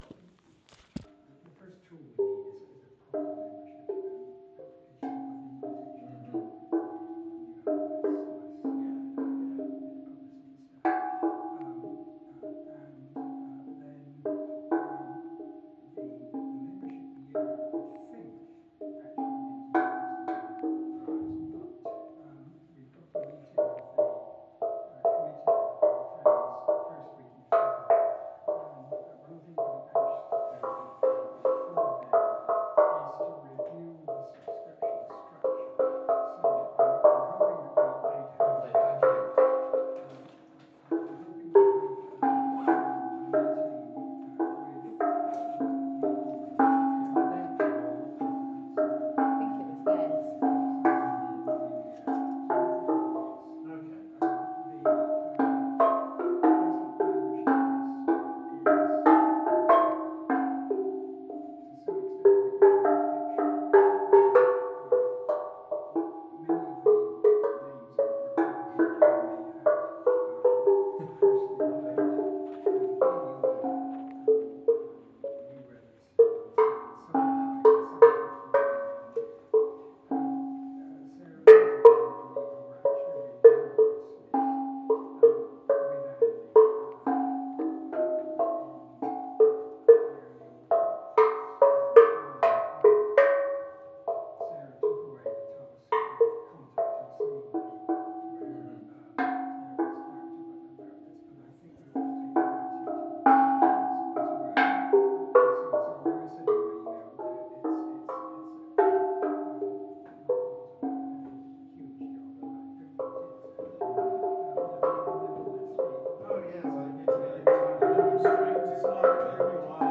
Bate collection steel drum